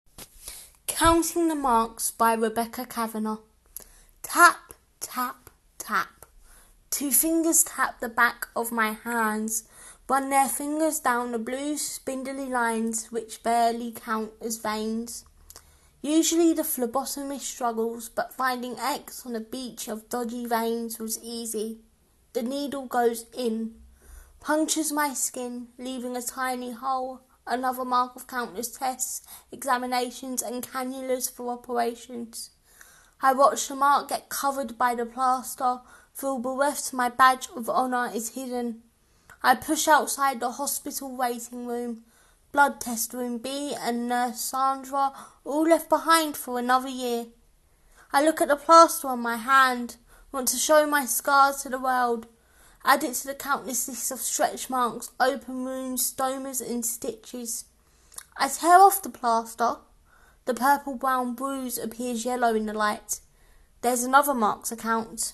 Click to hear this poem out loud.